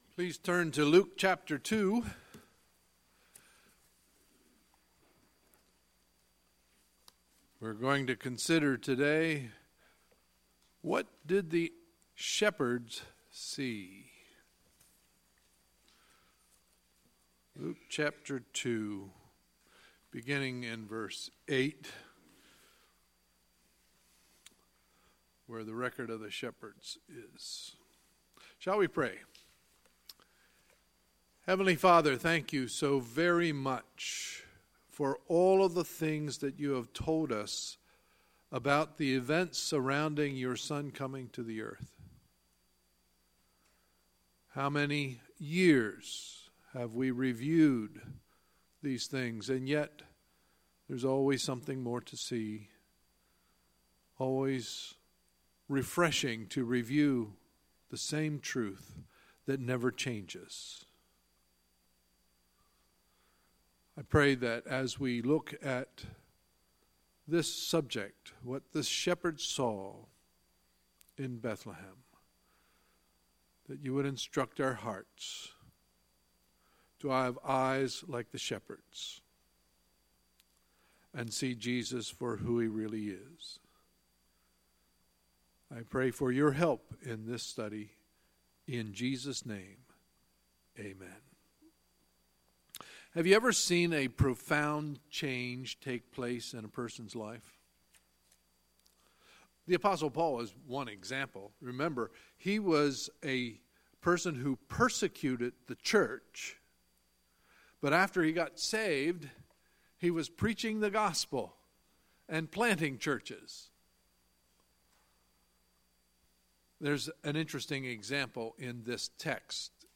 Sunday, December 24, 2017 – Sunday Morning Service
Sermons